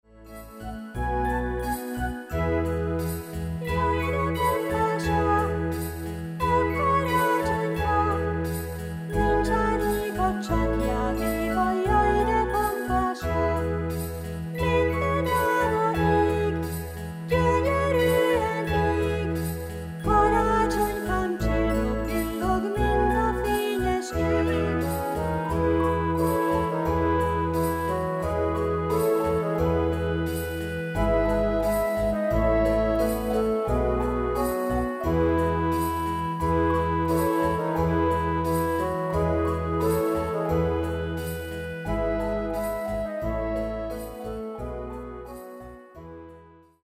ének
karácsonyi dalok babáknak